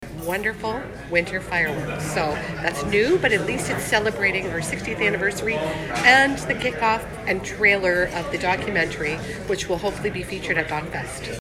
Councillor Kelly McCaw says this years Festival of Lights kick-off will be special.